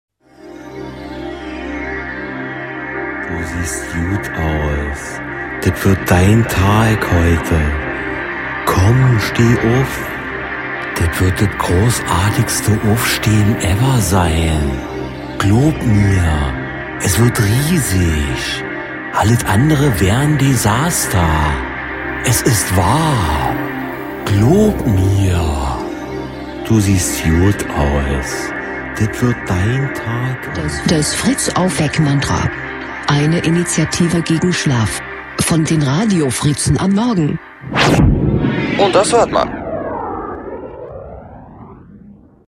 FritzAufweck-Mantra 08.02.17 (Trumpduktus) | Fritz Sound Meme Jingle
FritzAufweck-Mantra 08.02.17 (Trumpduktus)